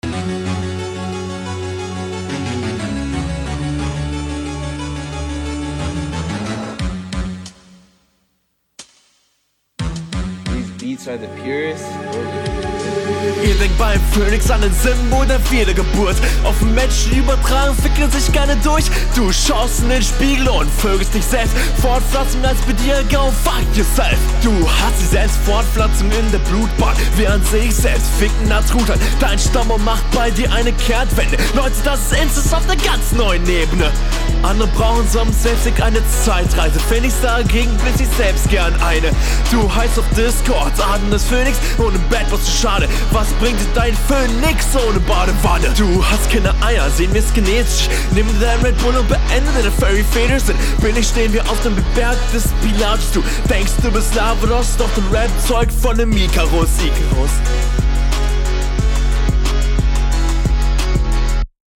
Das Nuscheln aus der letzten Runde ist hier noch genauso stark, aber deutlich Störender.
nuscheln but gut